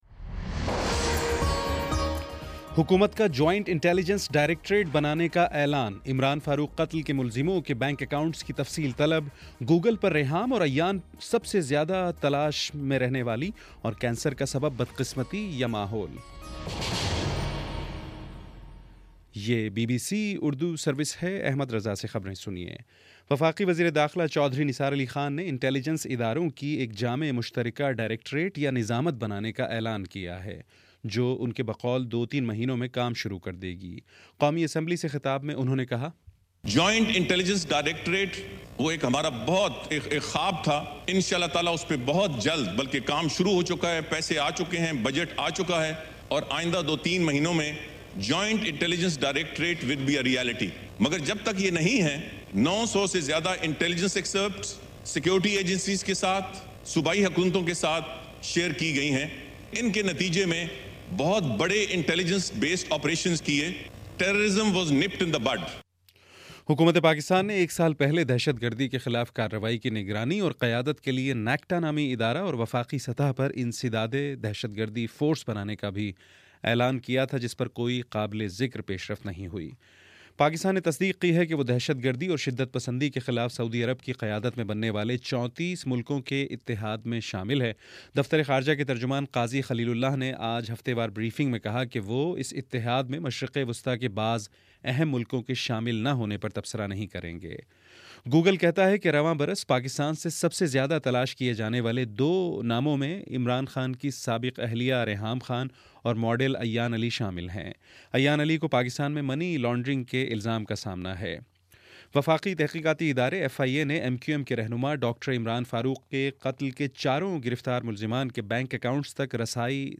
دسمبر 17 : شام چھ بجے کا نیوز بُلیٹن